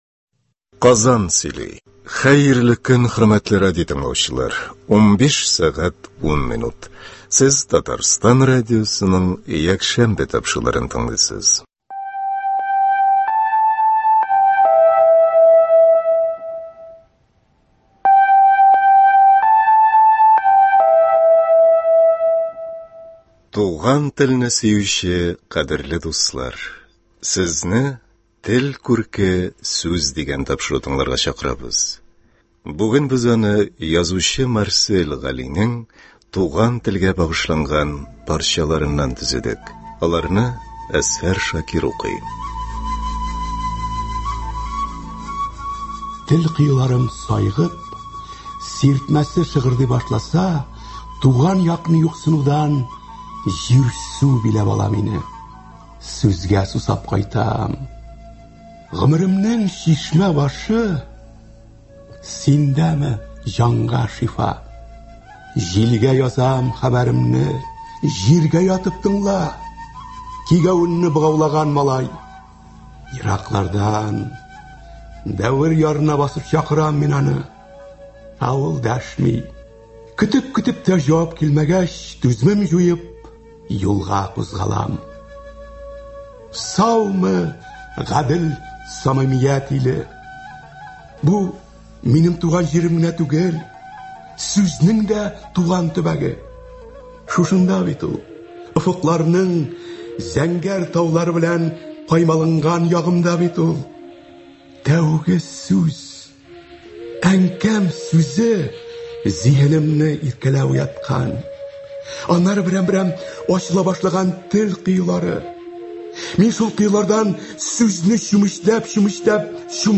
Туган телебезне камилрәк итеп үзләштерергә теләүче тыңлаучыларыбызга адресланган әлеге тапшыруны без язучы Марсель Галинең туган телгә багышланган парчаларыннан төзедек. Әсәрләрне Татарстанның һәм Россиянең халык артисты Әзһәр Шакиров укый.